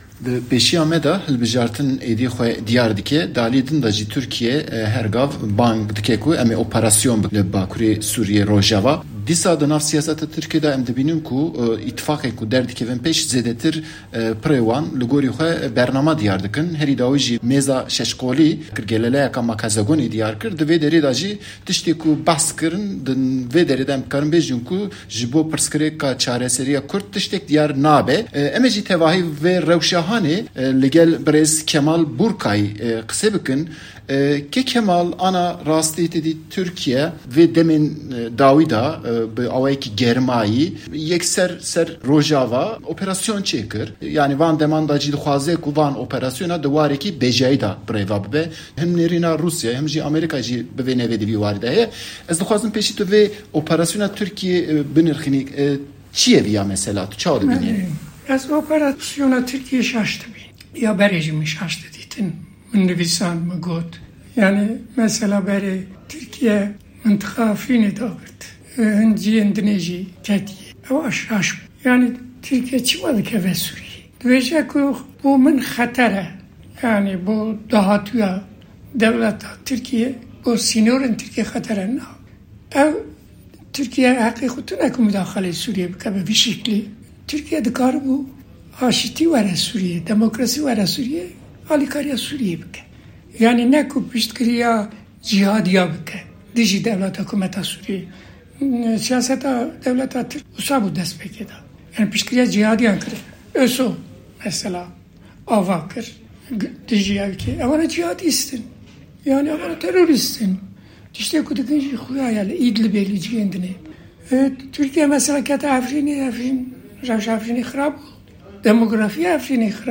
دەقی وتووێژەکەی دەنگی ئەمەریکا لەگەڵ کەمال بورقای